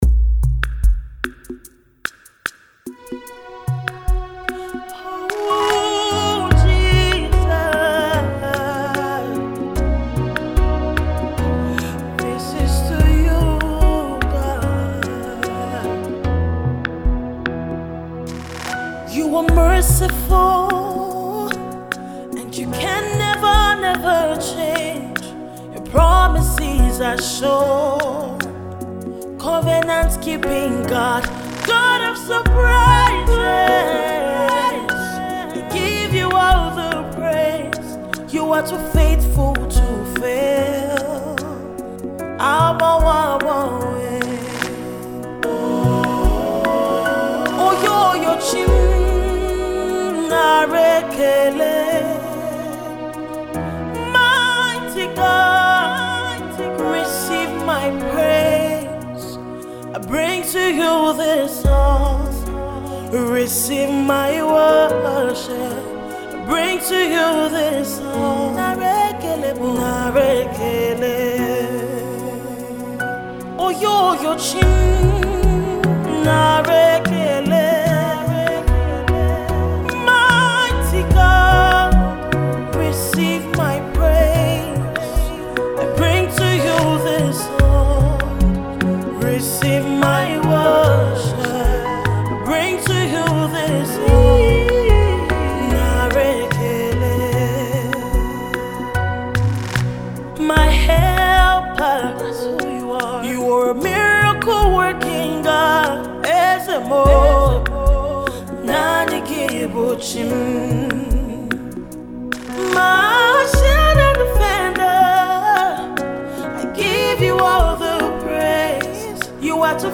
soul-stirring single